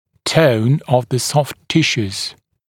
[təun əv ðə sɔft ‘tɪʃuːz] [-sjuː][тоун ов зэ софт ‘тишу:з] [-сйу:]тонус мягких тканей